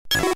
Cri de Roucool K.O. dans Pokémon Diamant et Perle.